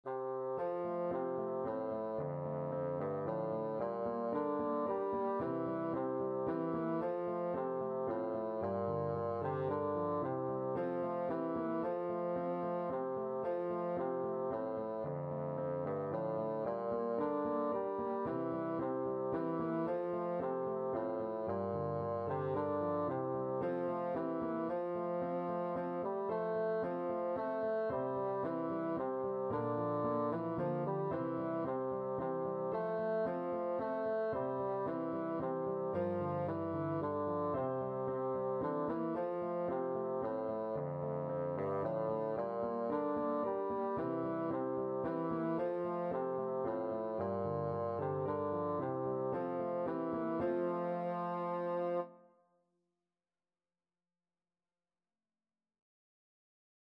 Bassoon
F major (Sounding Pitch) (View more F major Music for Bassoon )
3/4 (View more 3/4 Music)
Moderately Fast ( = c. 112)
Traditional (View more Traditional Bassoon Music)